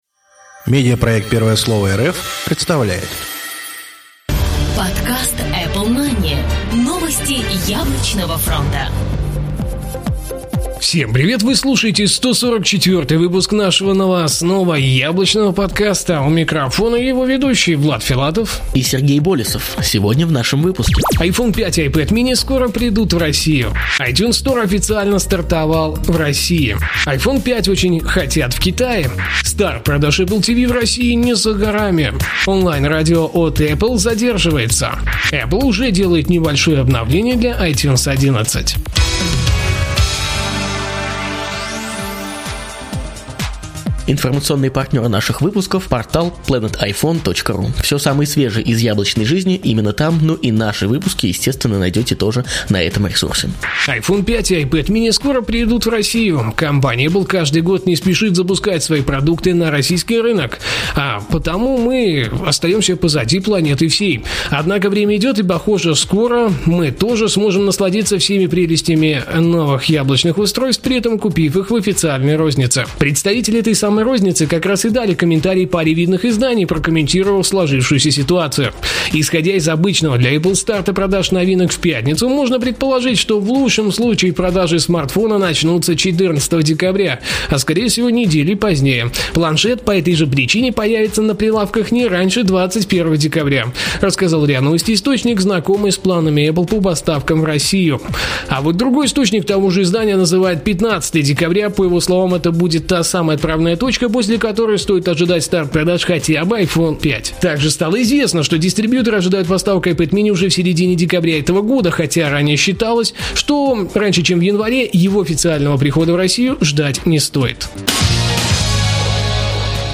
Жанр: новостной Apple-podcast
Битрейт аудио: 80-96, stereo